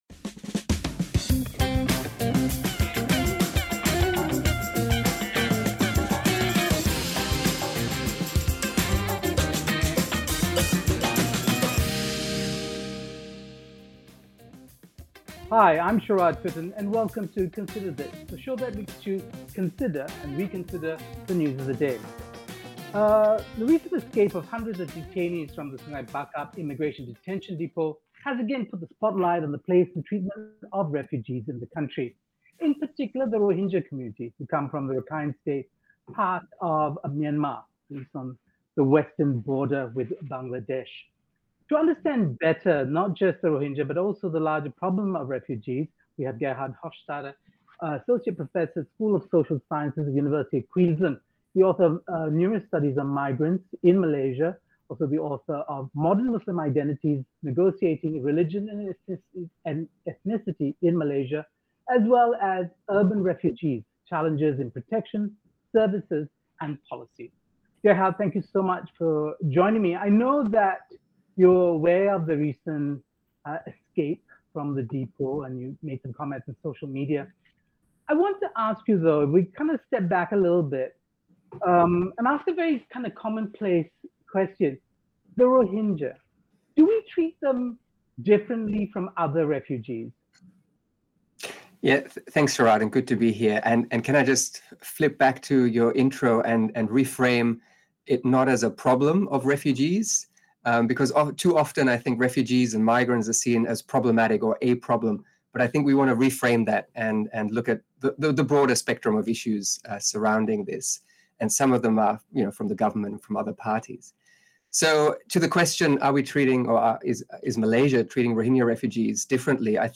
asks our guest the reasons for the discrepancy.